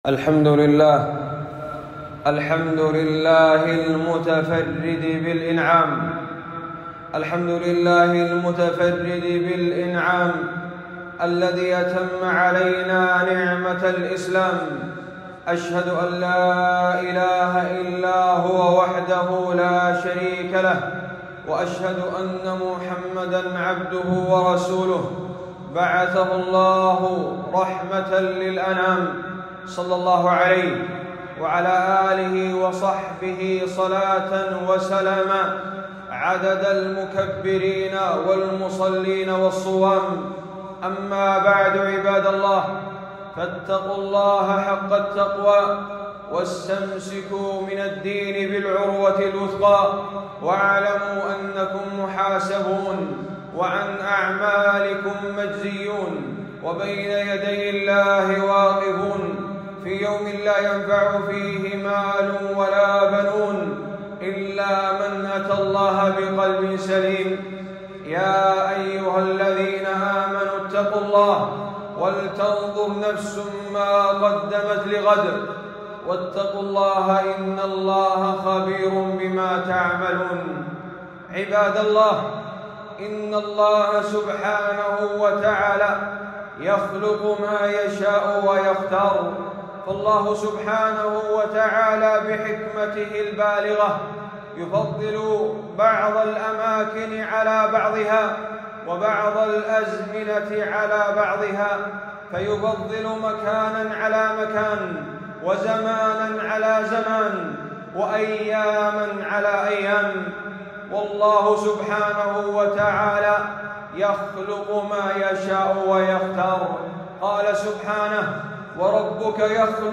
خطبة - يوم عرفة فضائل وأحكام